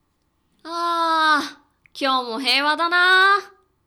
ボイス
中性